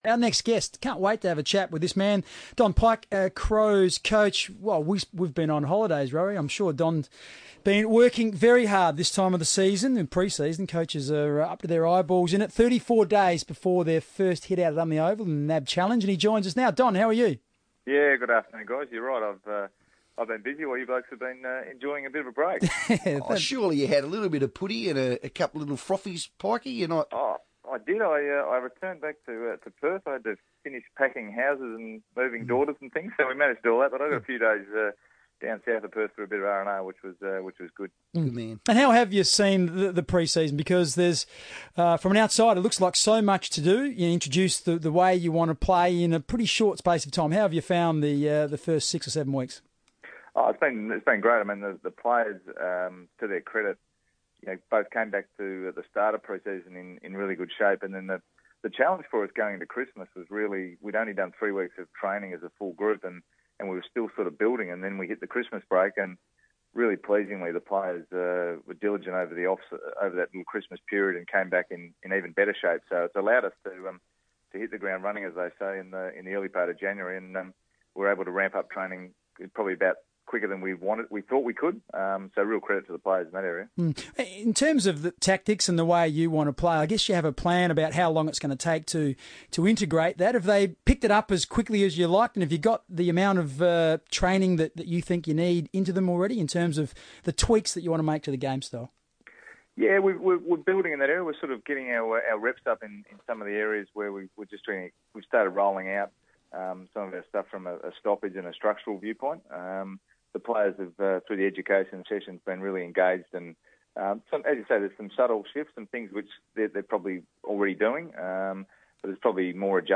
Don Pyke on FIVEaa